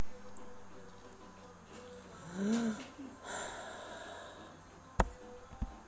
音频作品 " 平静的悲观主义叹息 - 声音 - 淘声网 - 免费音效素材资源|视频游戏配乐下载